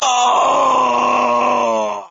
scream6.ogg